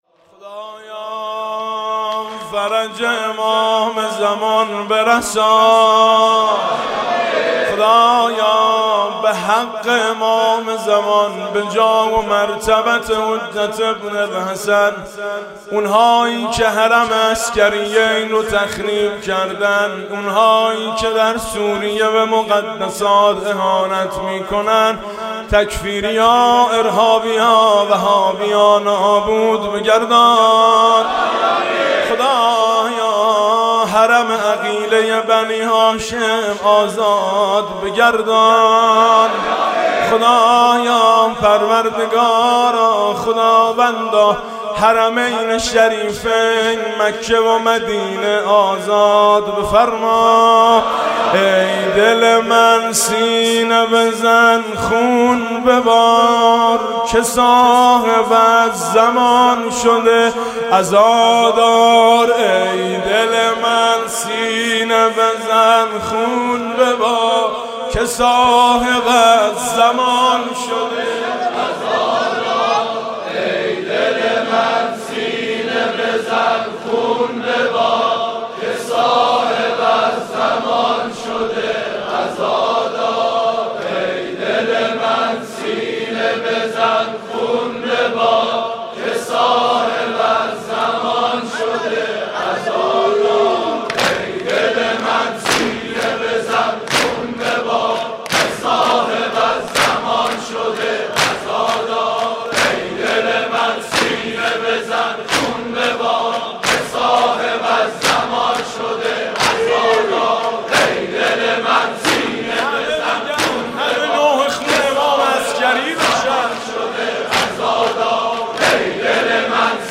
خیمه گاه - هیئت فاطمیون کوهنانی - مطیعی دودمه- ای دل من سینه بزن خون ببار